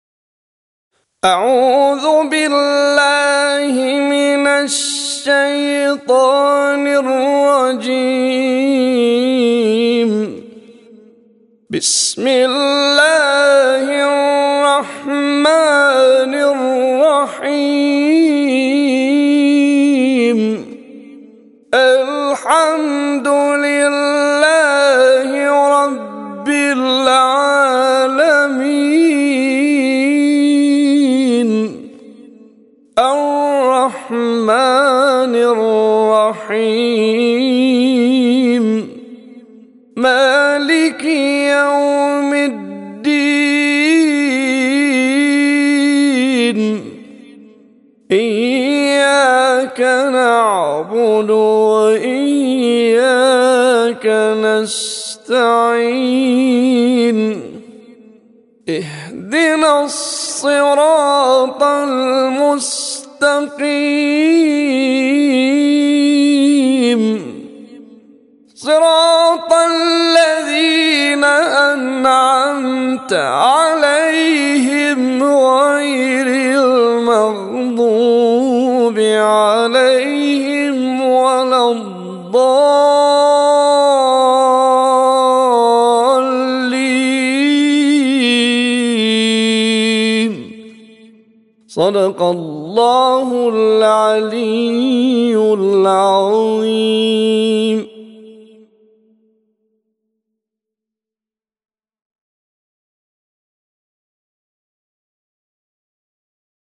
طور مصري